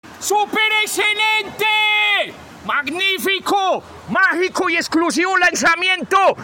super excelente maravilloso Meme Sound Effect